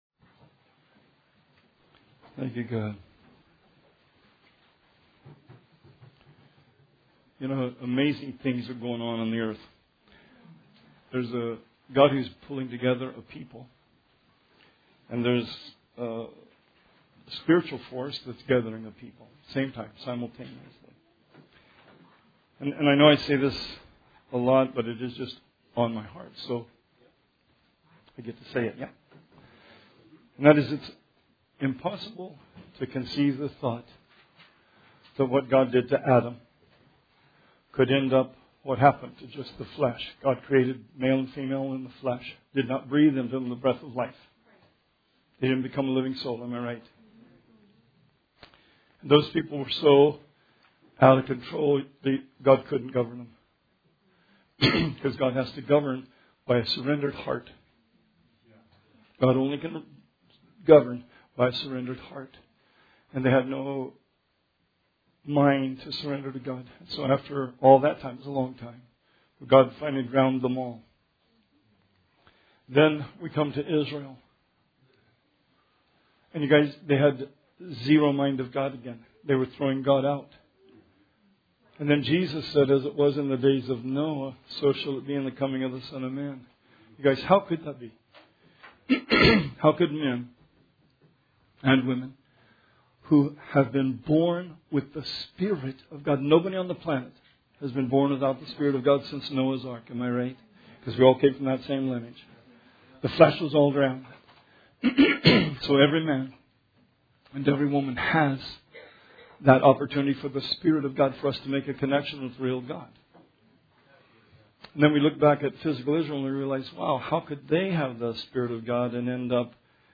Sermon 3/17/19